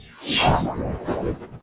swooshygate.ogg